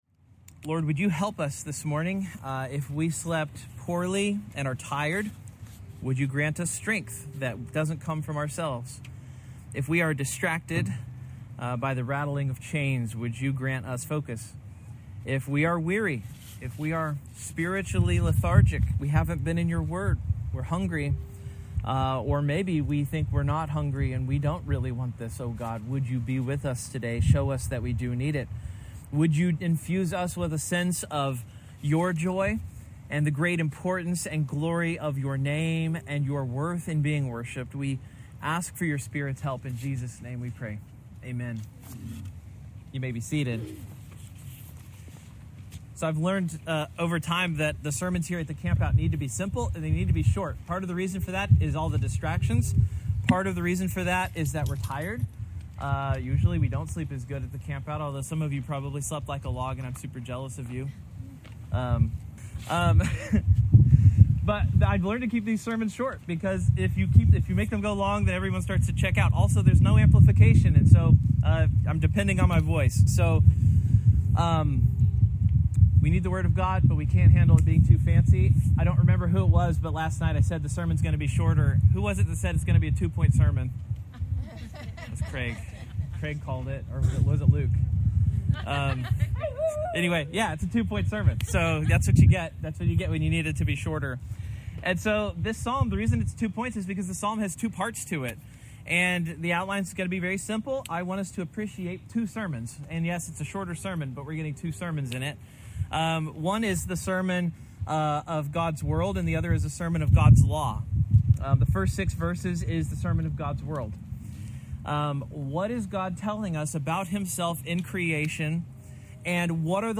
The Preaching Heavens (Preached at campground during Church campout)